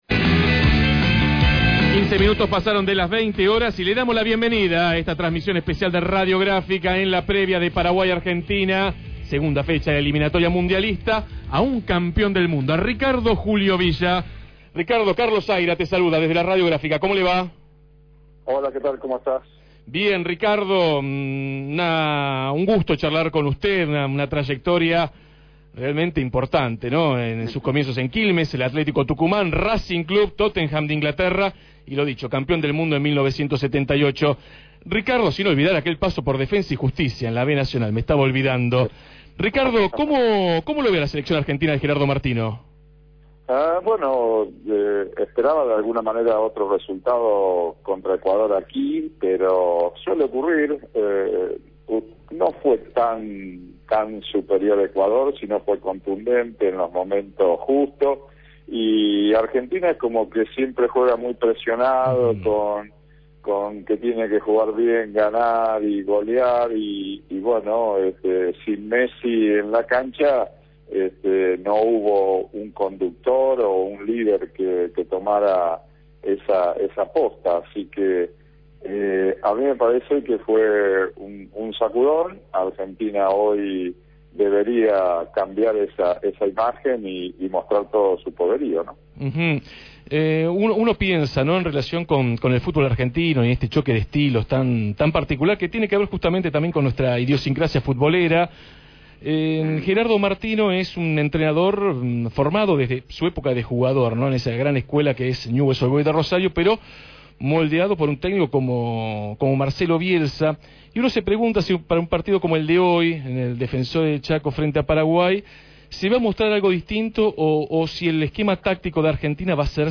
Así lo afirmó Ricardo Julio Villa, campeón del Mundo en Argentina 1978, durante la transmisión especial realizada por Radio Gráfica por el partido de eliminatoria mundialista entre Paraguay-Argentina.